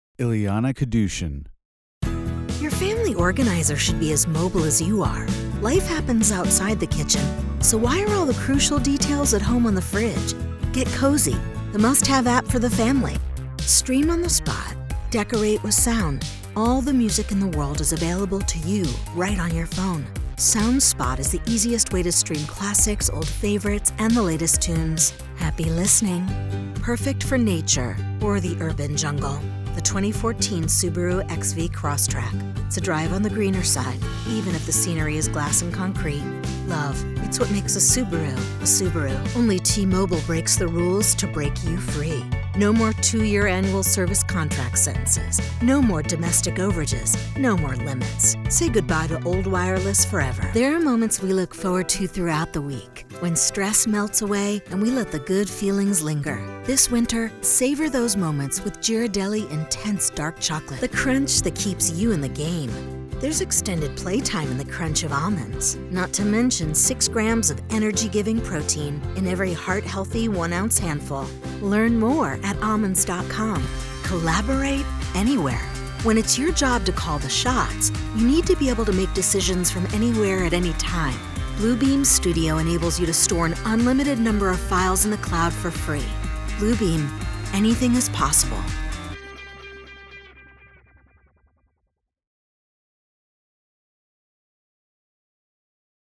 Commerical Reel